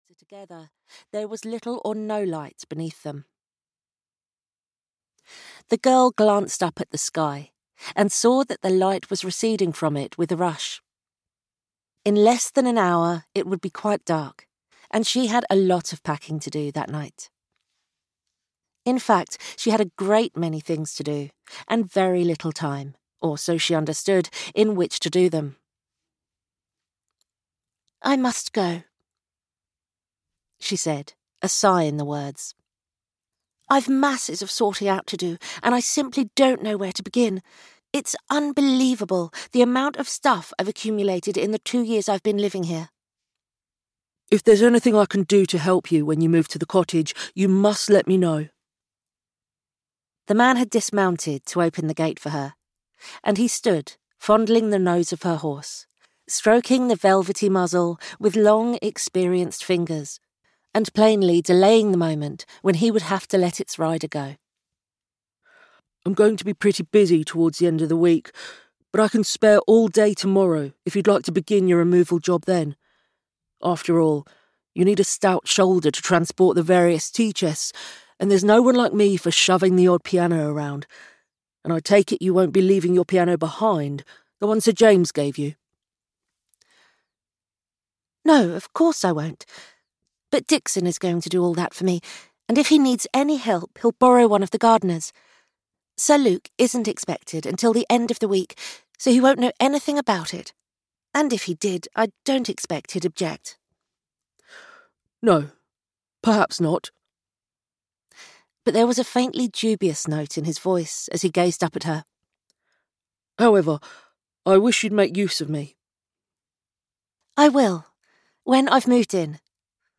Wild Sonata (EN) audiokniha
Ukázka z knihy